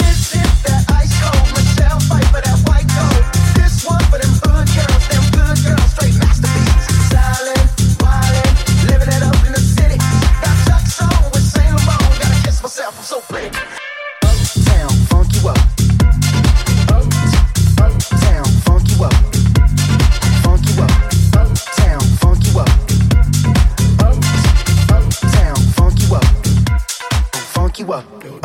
Genere: deep, house, club, remix